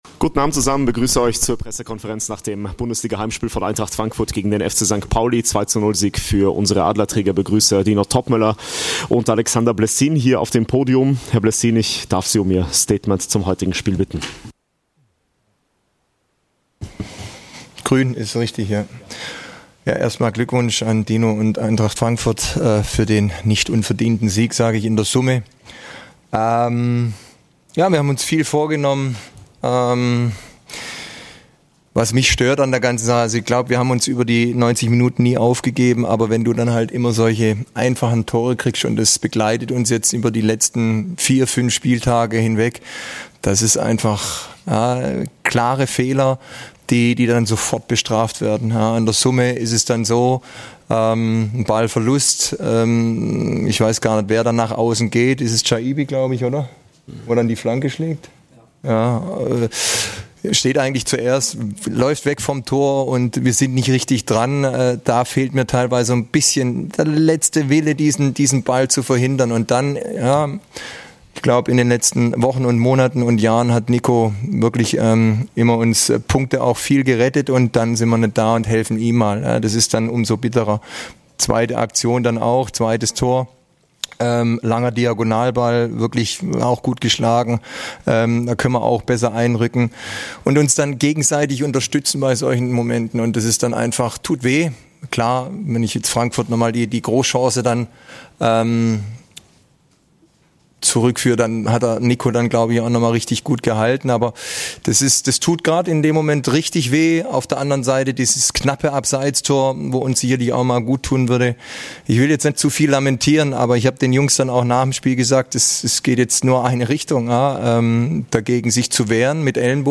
"Eintracht Aktuell" - Stimmen aus der Eintracht Welt